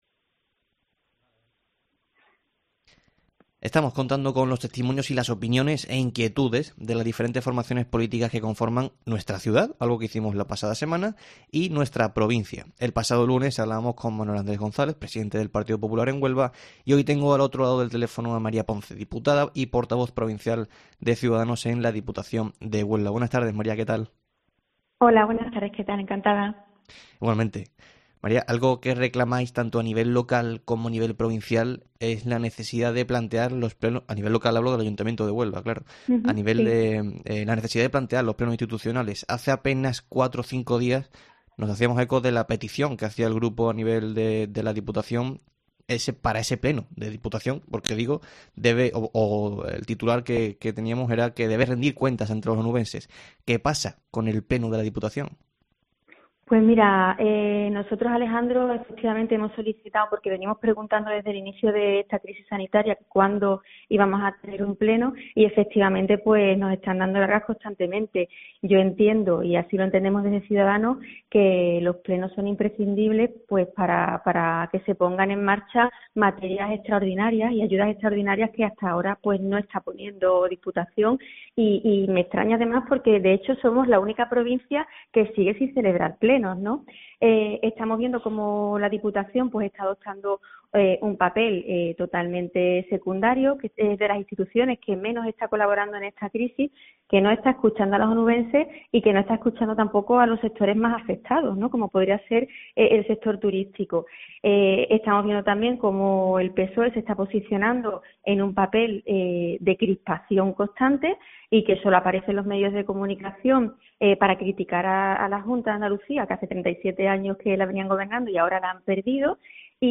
AUDIO: En el tiempo local de Herrera en COPE hemos hablado con María Ponce, diputada y portavoz de Cs en la Diputación, sobre diferentes cuestiones...